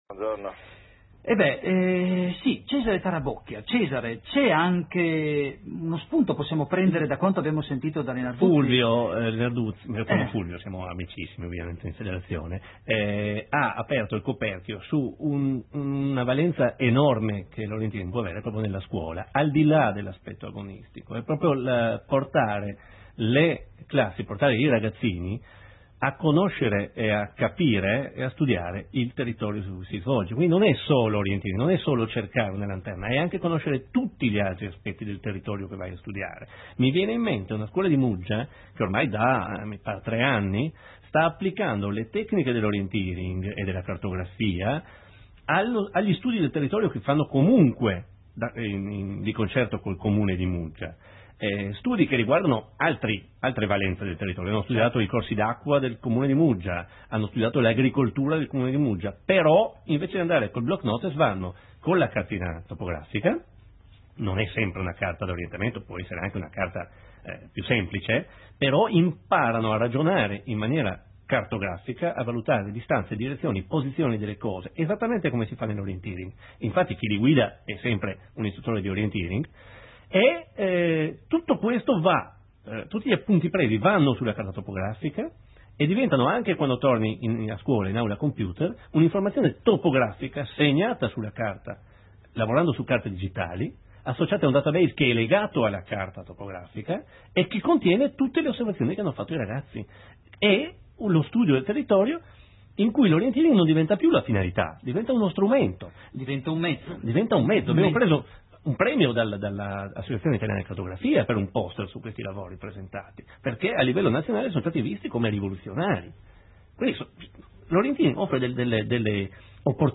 Intervista radiofonica